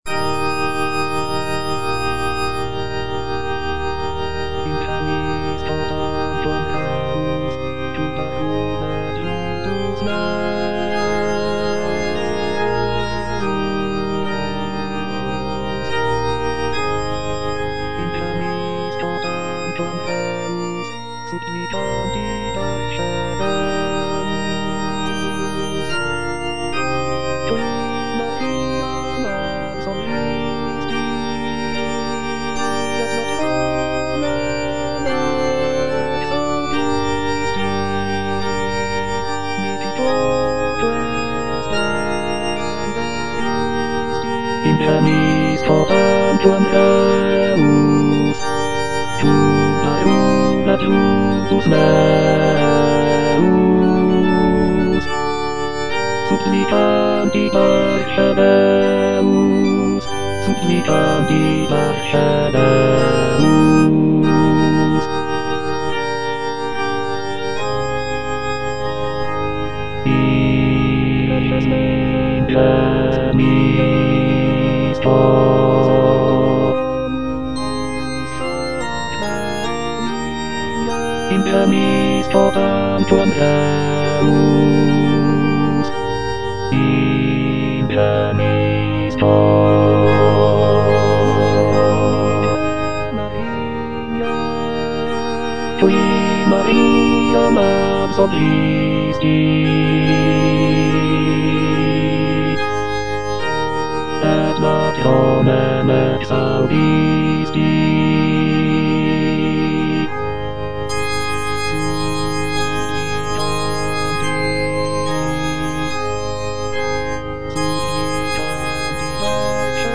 (bass II) (Emphasised voice and other voices) Ads stop
is a sacred choral work rooted in his Christian faith.